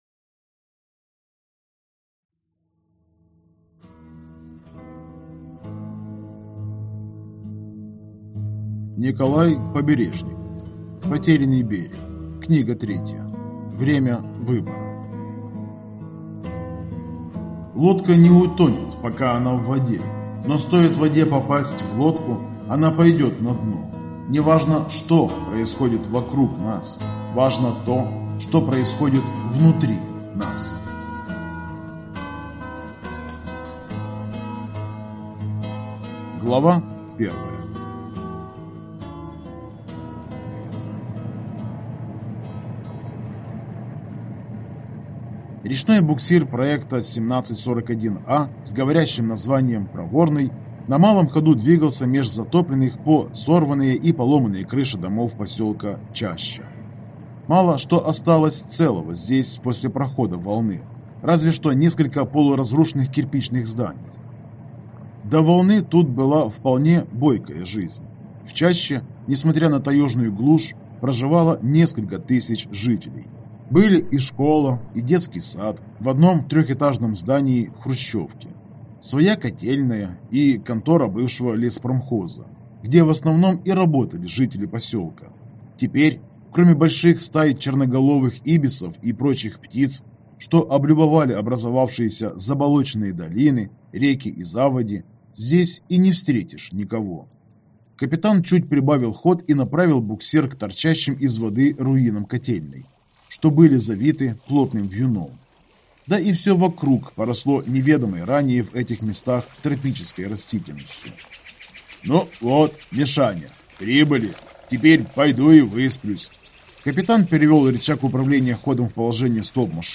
Аудиокнига Бремя выбора | Библиотека аудиокниг